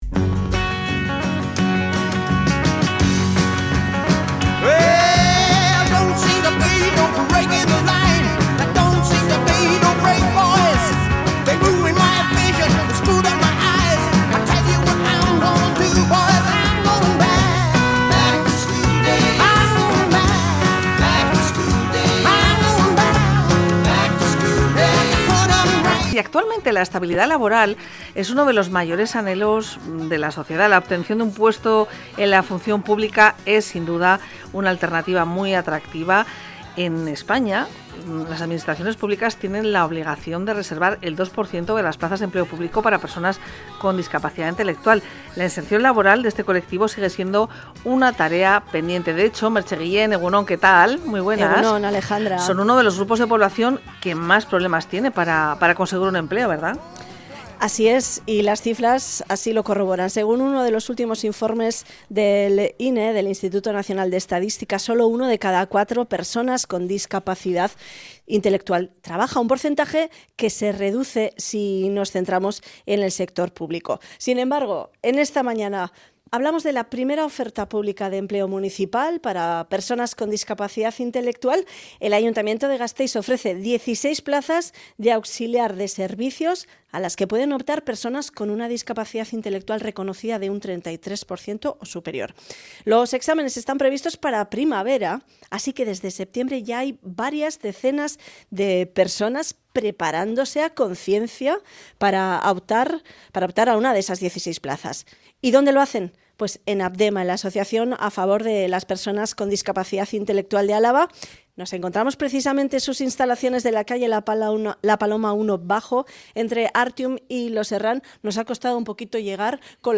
LA UNIDAD MÓVIL DE RADIO VITORIA VISITA EL PROGRAMA “OPES ACCESIBLES” DE APDEMA | Apdema